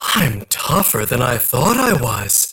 61 KB Viscous voice line (unfiltered) - I'm tougher than I thought I was! 1